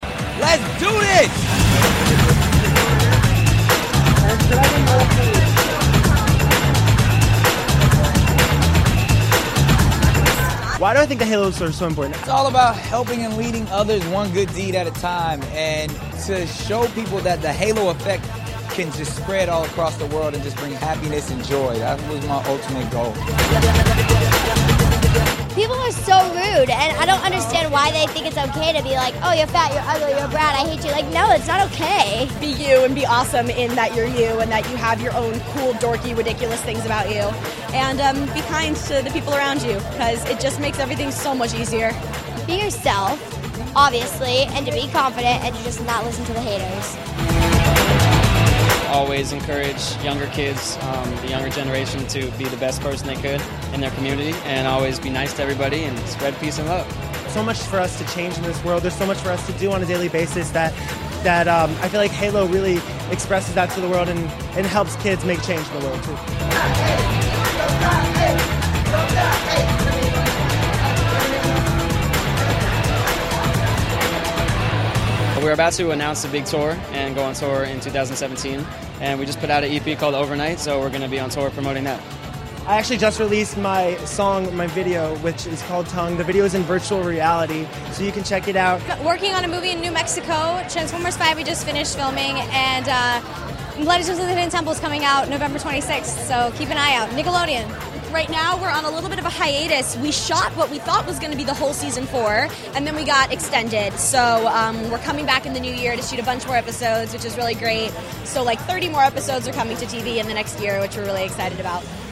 Nick Cannon, Jojo Siwa, Kira Kosarin and Jake Miller talk about the halo effect, being your “dorky self” and their latest artistic endeavors at the 2016 Nickelodeon Halo Awards.